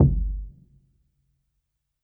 Kick (Pursuit of Happiness).wav